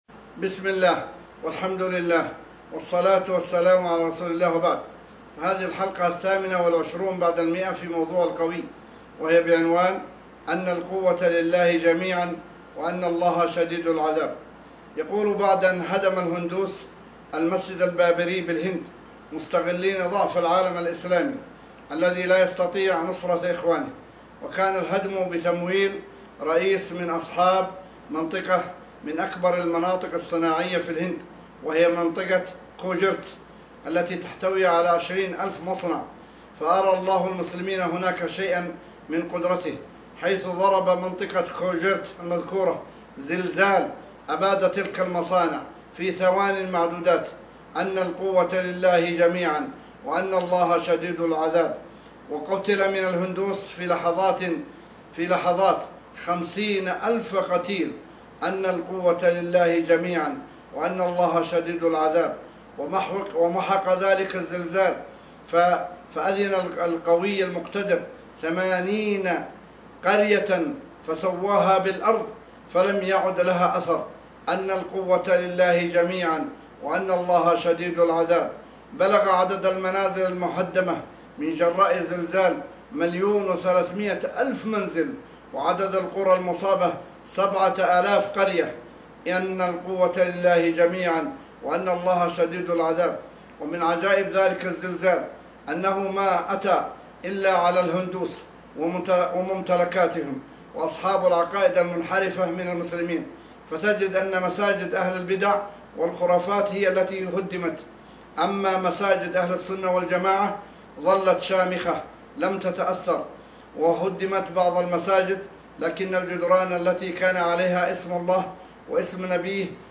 أسمـــــــــاء الله الحسنـــــــــى وصفاتــــــه الحلقـــــــة الثامنة والعشرون بعد المائة في موضــــوع القوي